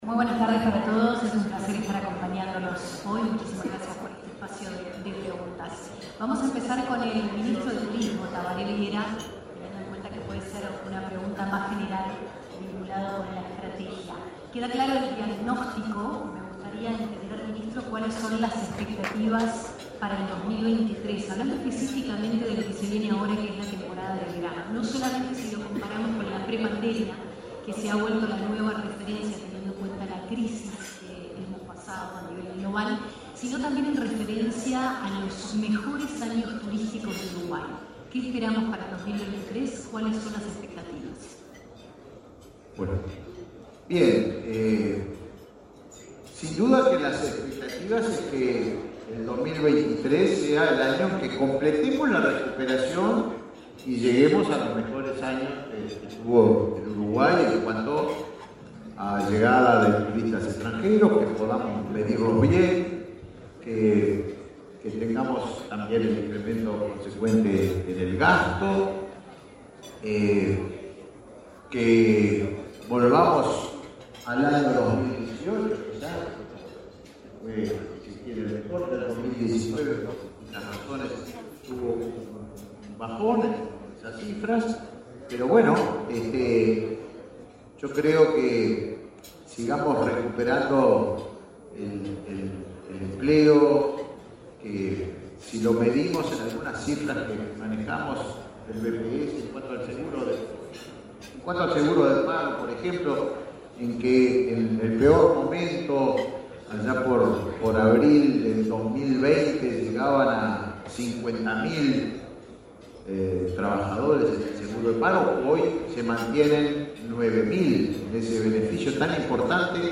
Autoridades responden preguntas de la prensa en el almuerzo de trabajo de ADM
Autoridades responden preguntas de la prensa en el almuerzo de trabajo de ADM 08/11/2022 Compartir Facebook X Copiar enlace WhatsApp LinkedIn Autoridades del Gobierno participaron, este 8 d noviembre, en el almuerzo de trabajo denominado Uruguay de Cara a la Temporada 2023. Durante el evento, el secretario de la Presidencia, Álvaro Delgado, y los intendentes respondieron preguntas relacionadas a diversos temas.